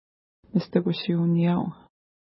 Pronunciation: mistukuʃi:u-nija:w
Pronunciation